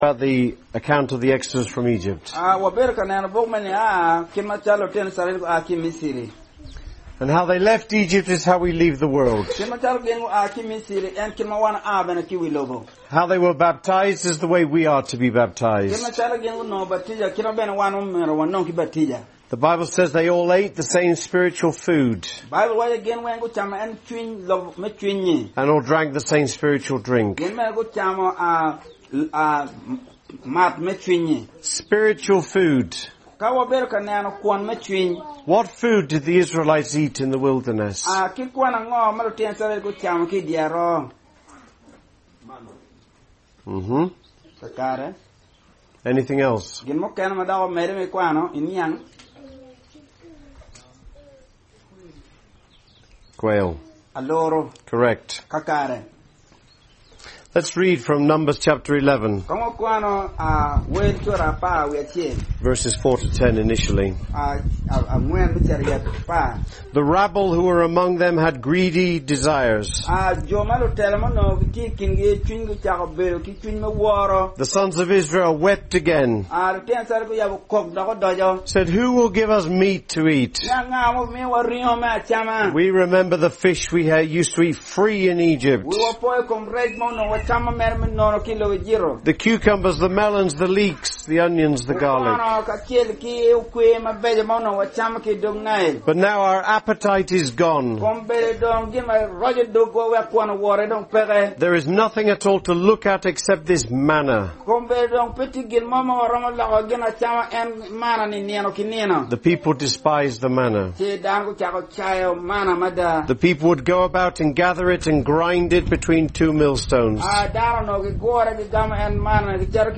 Recorded at Amuru, Northern Uganda with translation into the Luo language.